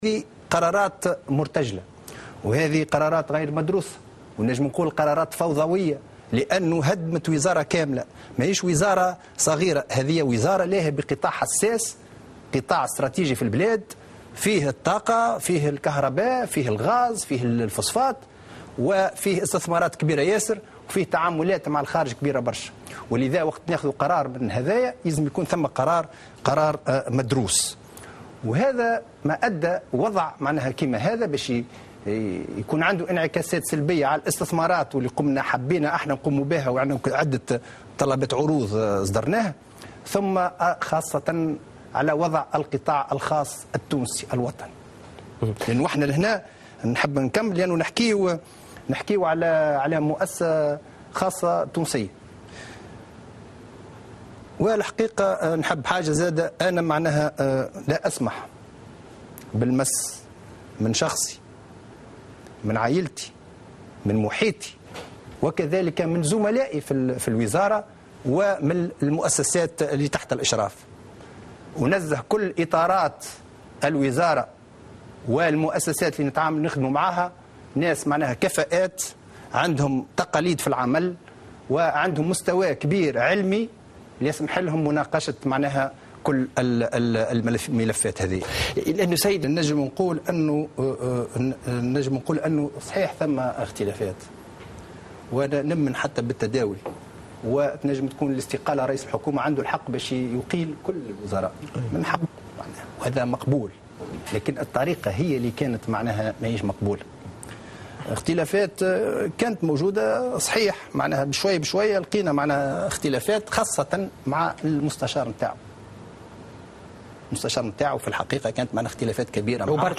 وأضاف في مداخلة له على "قناة نسمة" أن هذه القرارات هدمت الوزارة وسيكون لها انعكاسات سلبية على الاستثمار في القطاع.